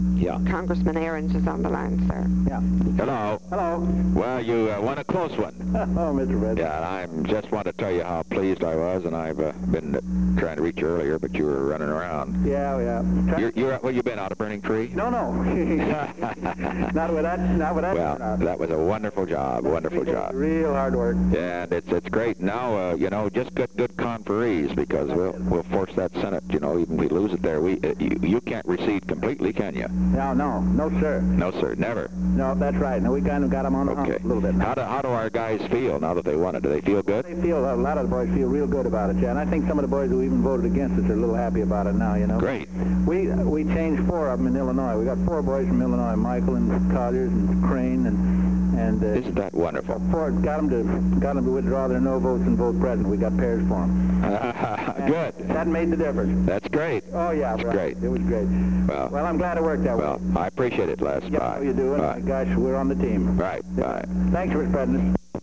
Secret White House Tapes
Location: White House Telephone
The President talked with Leslie C. Arends.